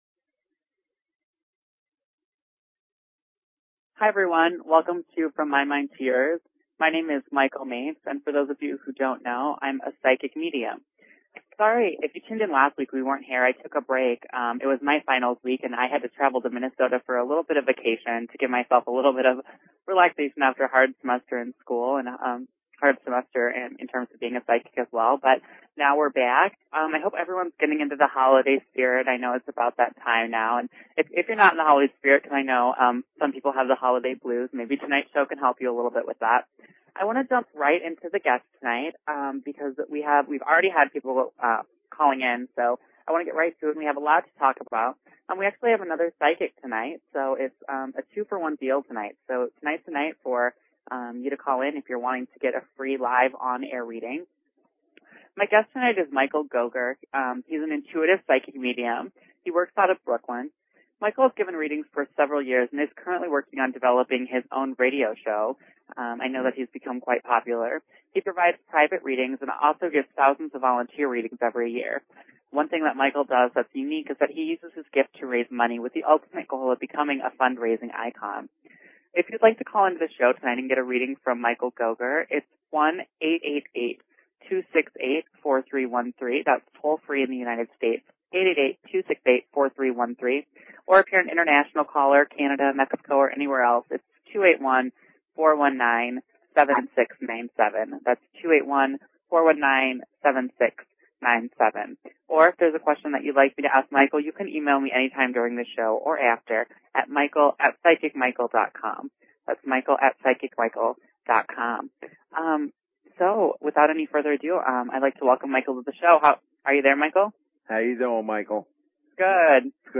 Talk Show Episode, Audio Podcast, From_My_Mind_To_Yours and Courtesy of BBS Radio on , show guests , about , categorized as